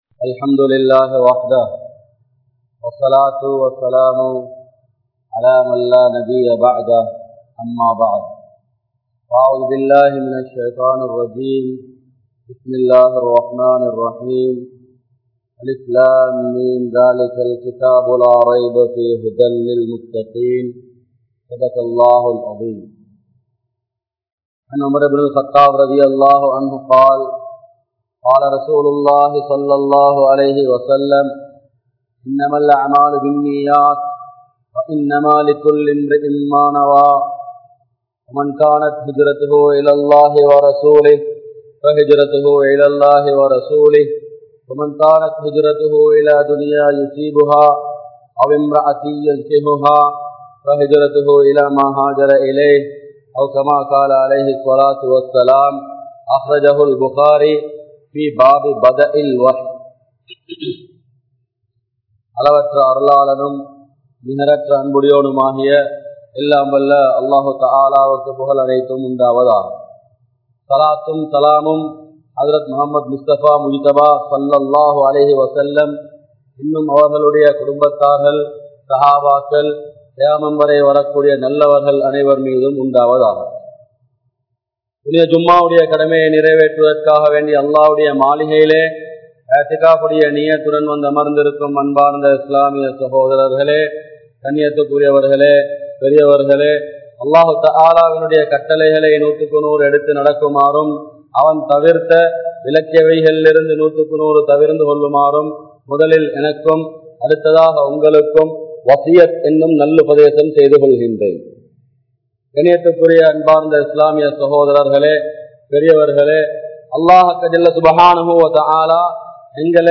Manitha Neayam(Humanity) | Audio Bayans | All Ceylon Muslim Youth Community | Addalaichenai